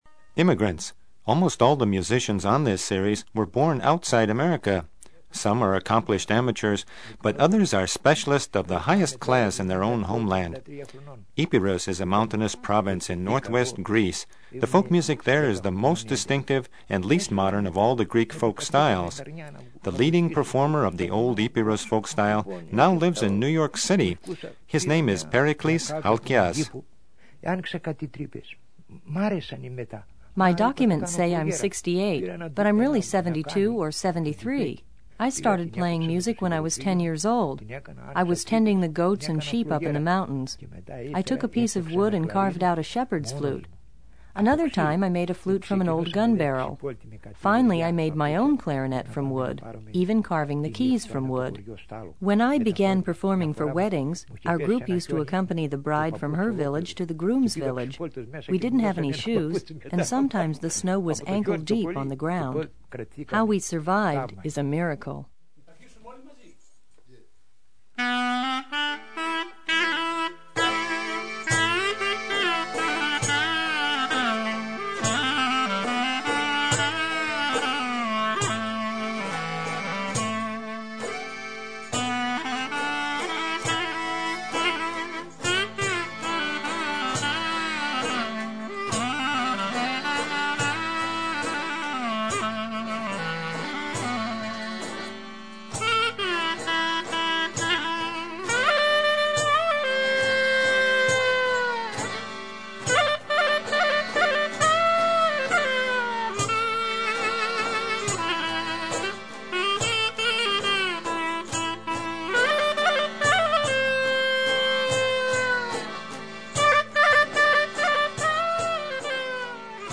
GREEK   Funky dance rhythms from Epirus in NYC; lyra fiddle in California